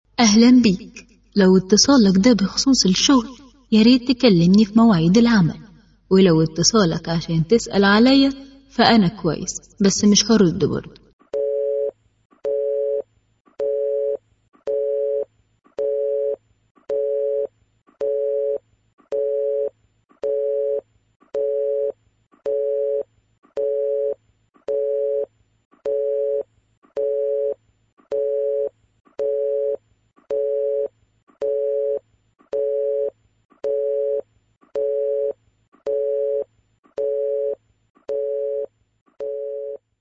ترحيبي